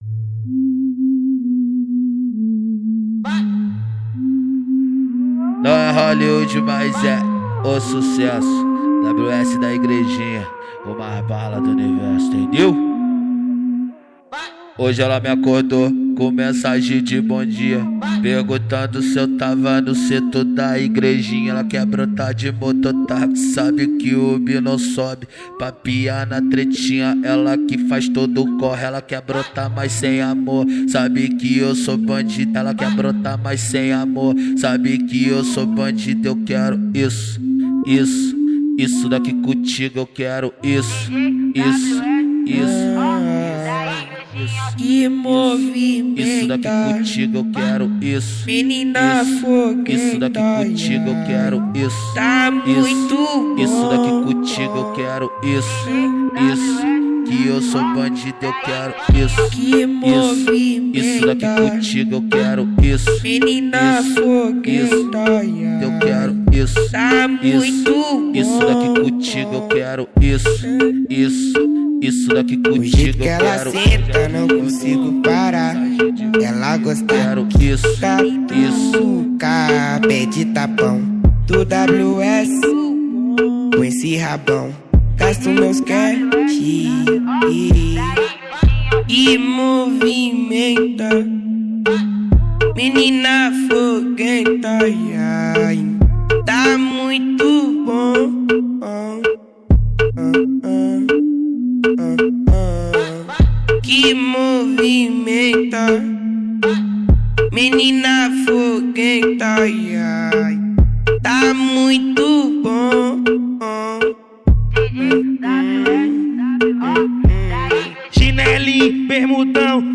Gênero: Phonk